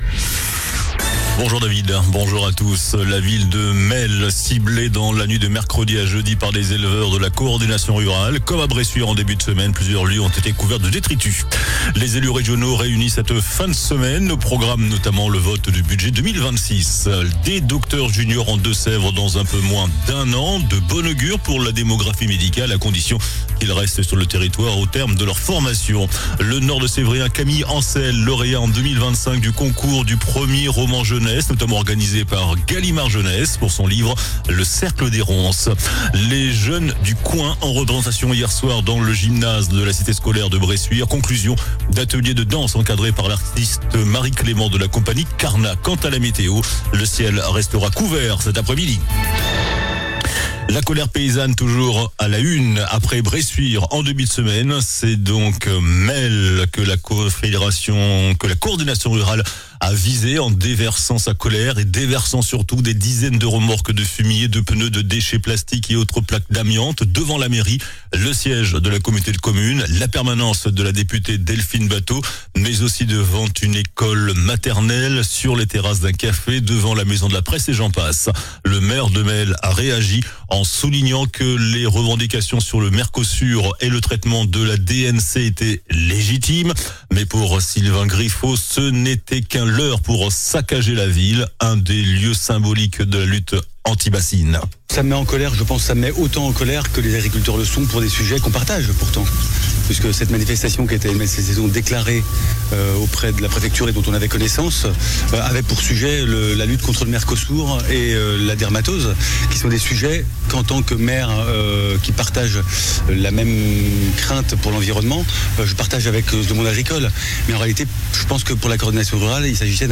JOURNAL DU VENDREDI 19 DECEMBRE ( MIDI )